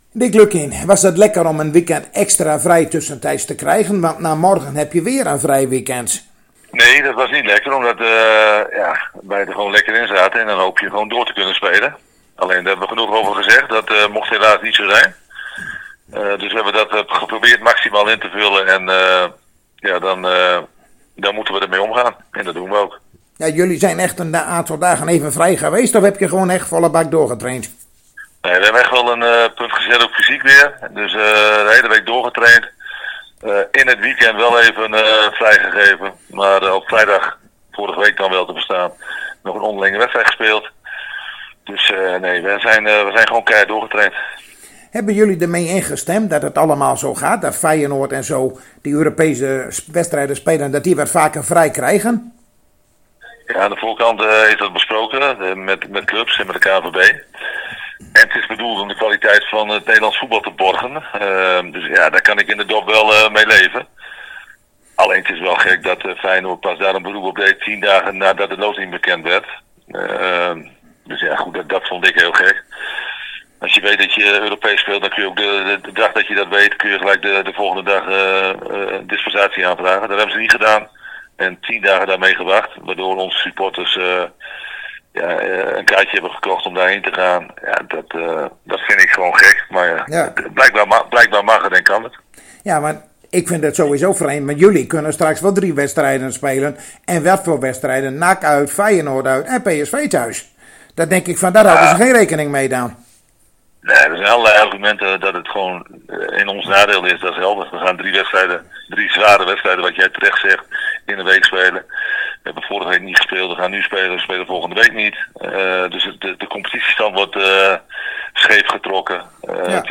Zojuist spraken wij weer met trainer Dick Lukkien in aanloop naar de wedstrijd van morgen tegen Fortuna Sittard.